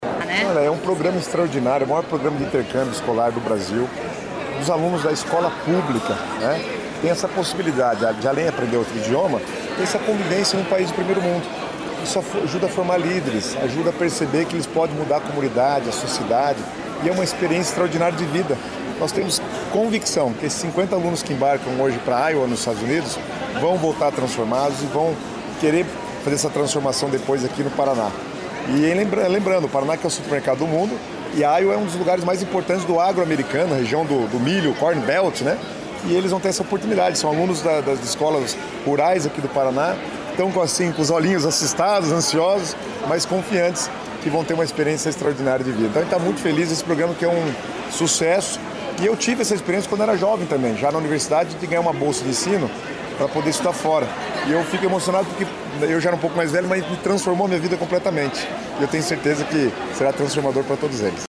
Sonora do secretário Estadual das Cidades, Guto Silva, sobre o embarque de 50 estudantes para os EUA pelo Ganhando o Mundo Agrícola